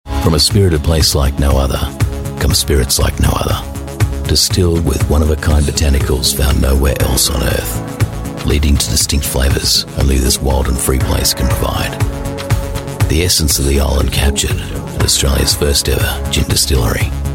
AU ENGLISH